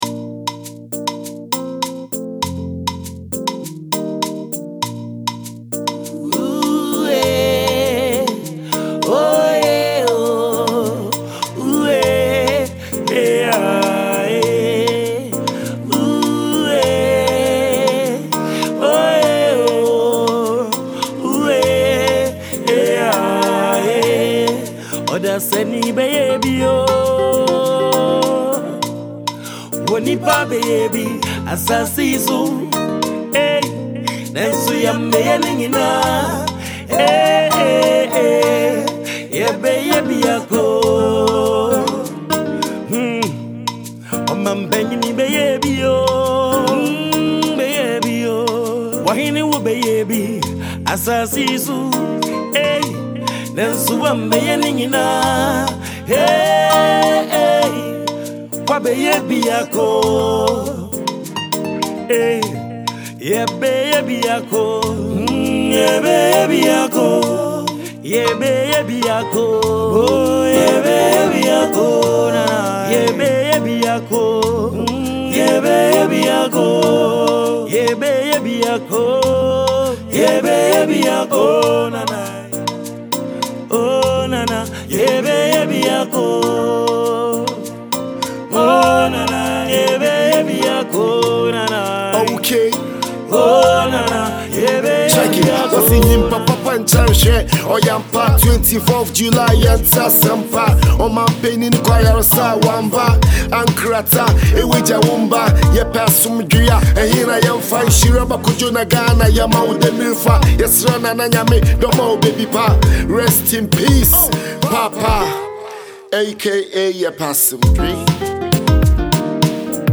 dirge song